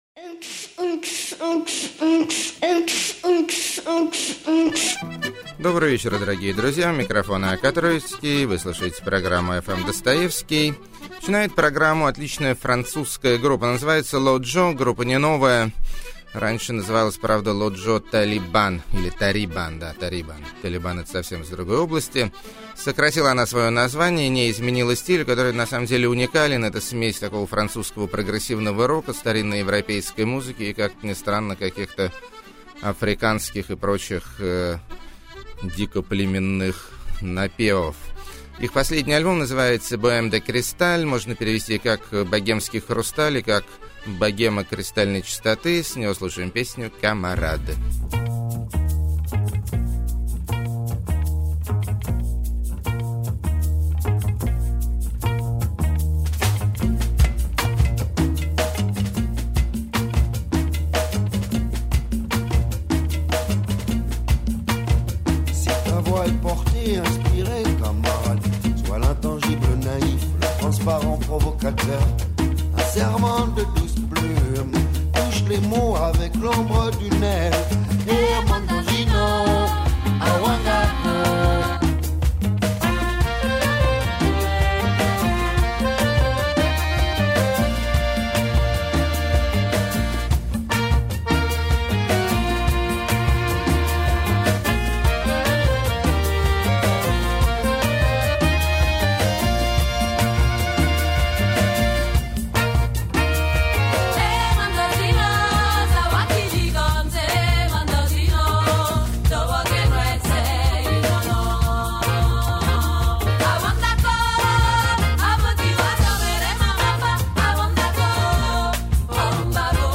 Лаконичный Анекдотичный Хип-хоп.
Качественное Трип-хоп Мяуканье.
Модная Аналоговая Lo-fi Психоделия.
Неописуемая Электро-акустическая Музыка.] 13.